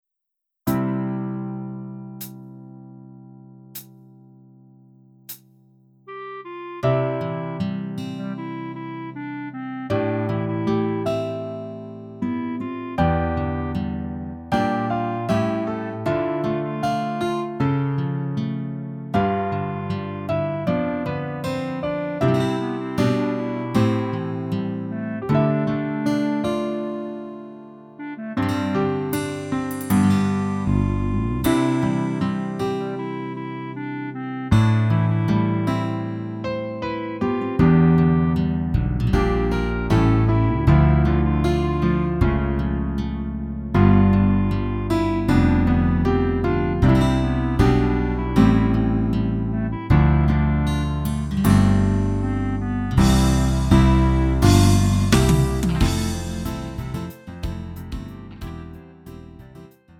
음정 -1키 3:45
장르 구분 Lite MR